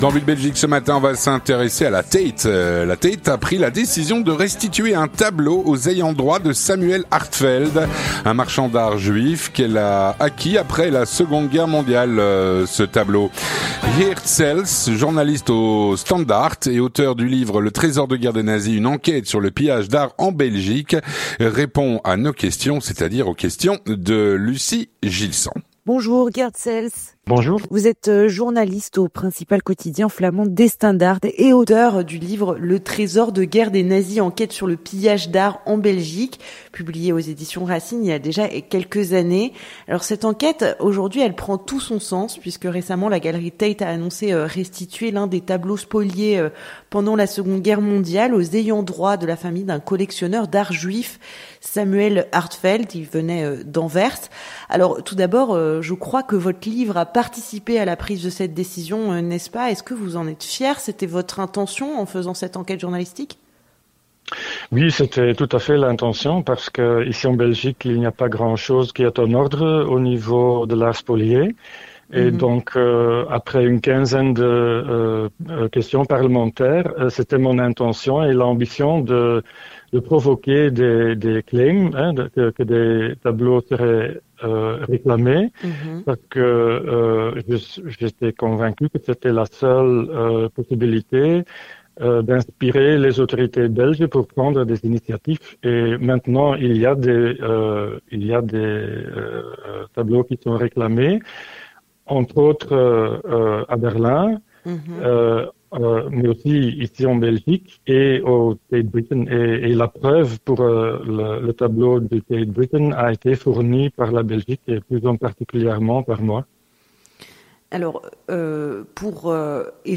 répond à nos questions.